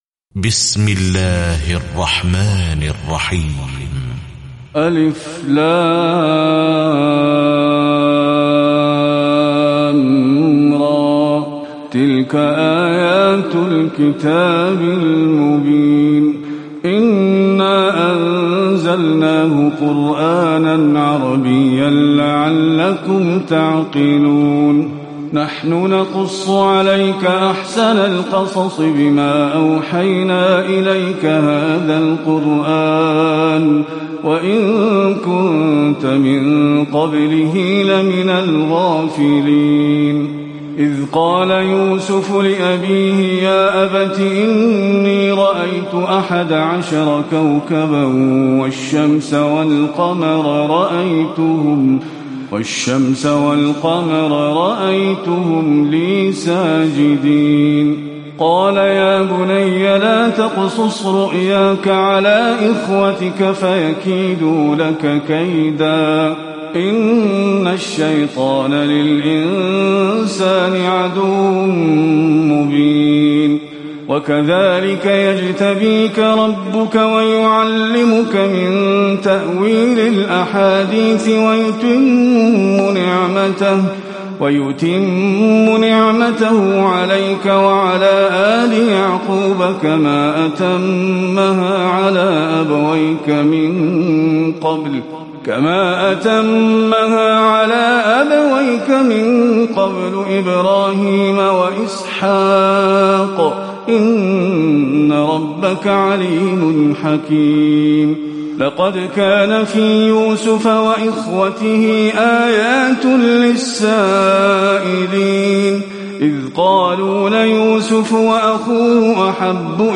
تراويح الليلة الثانية عشر رمضان 1438هـ سورة يوسف (1-111) Taraweeh 12 st night Ramadan 1438H from Surah Yusuf > تراويح الحرم النبوي عام 1438 🕌 > التراويح - تلاوات الحرمين